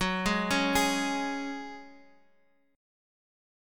Gbsus2 chord